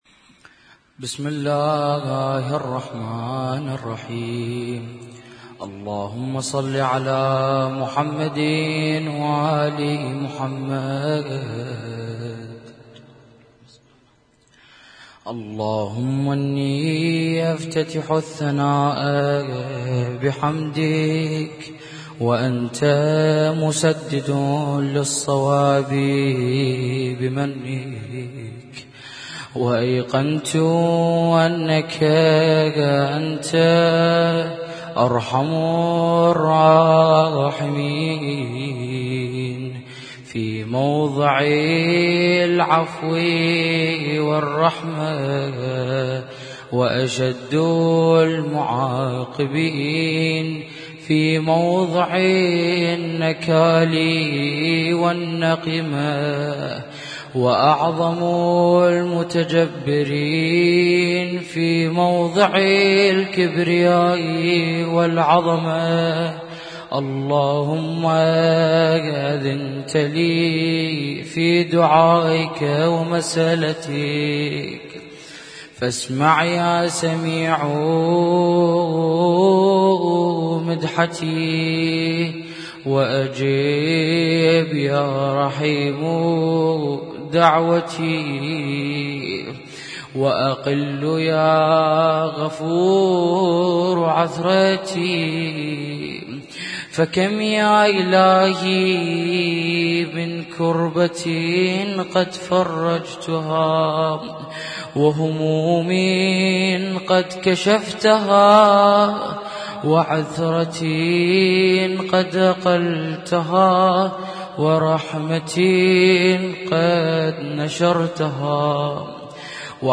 اسم التصنيف: المـكتبة الصــوتيه >> الادعية >> دعاء الافتتاح
البث المباشر - حسينية النور